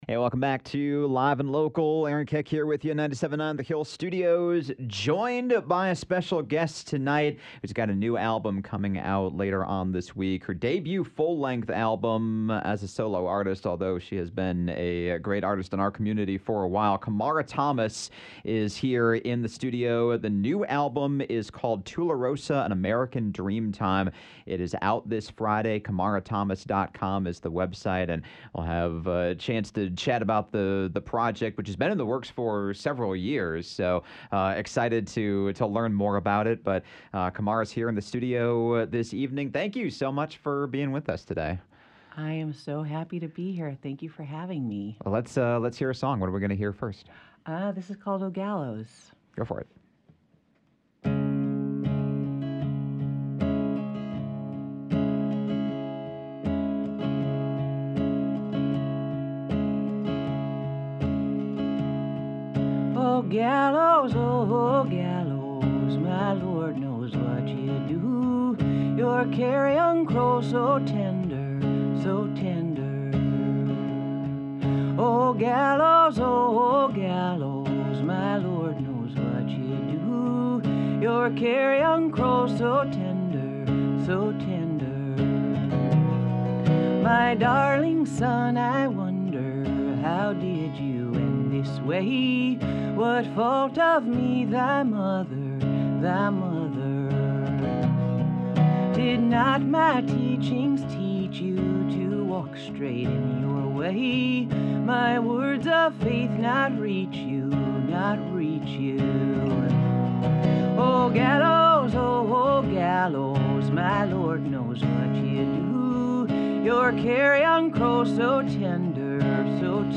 She also played three songs